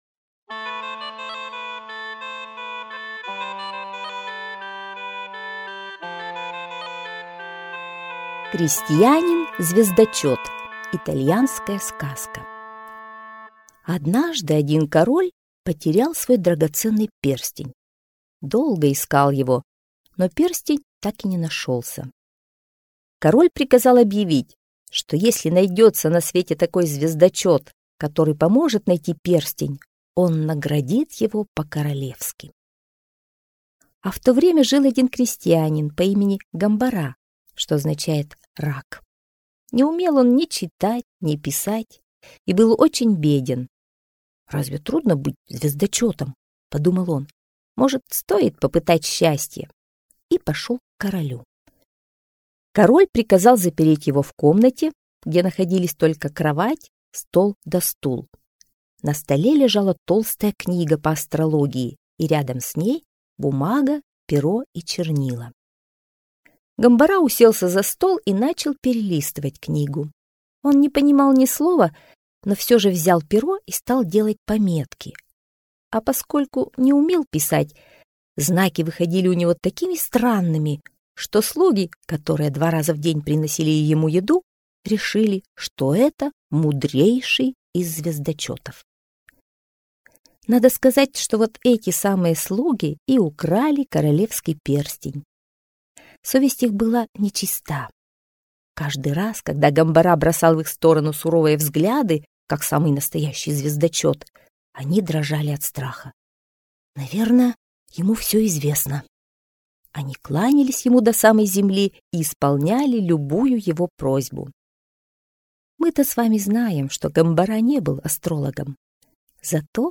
Крестьянин-звездочет – итальянская аудиосказка